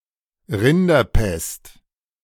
Rinderpest is believed to have originated in Asia, and to have spread by transport of cattle.[5][6][7] The term Rinderpest (German: [ˈʁɪndɐˌpɛst]